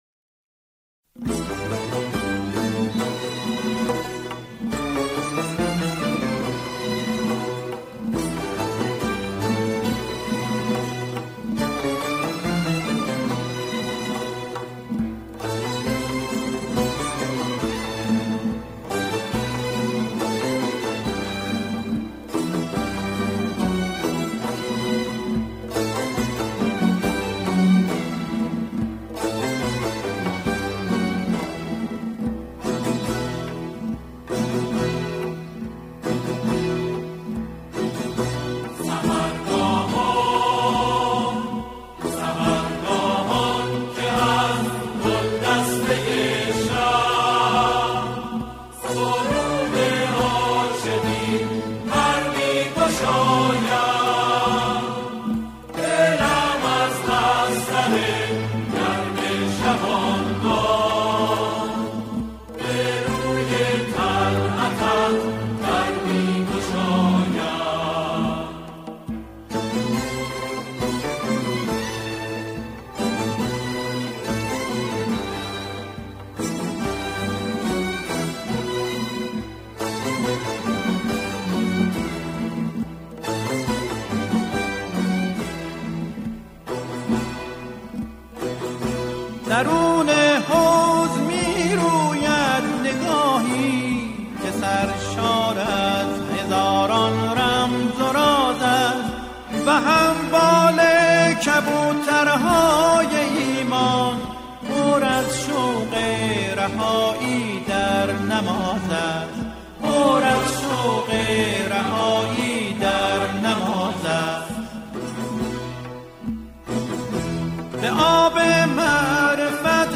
همخوانان، این قطعه را با شعری درباره نماز اجرا می‌کنند.